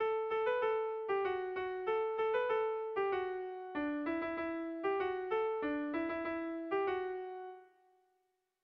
Sehaskakoa
Lauko txikia (hg) / Bi puntuko txikia (ip)